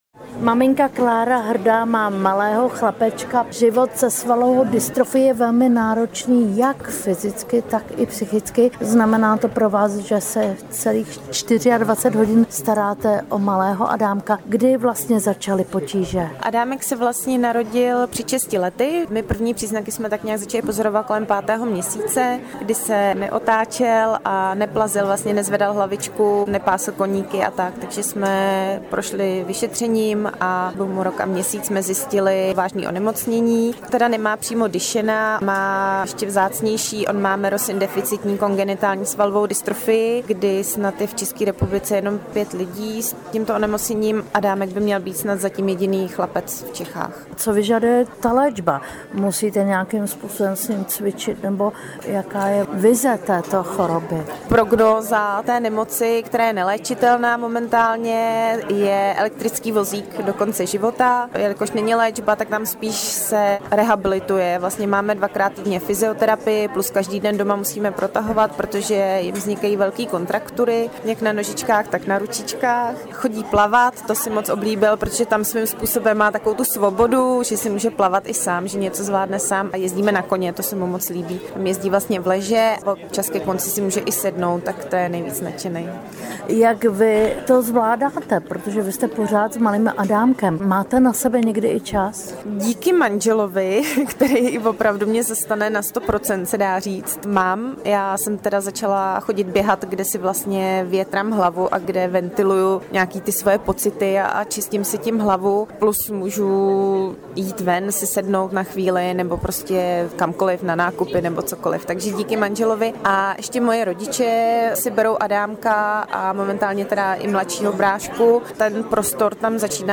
AUDIO rozhovor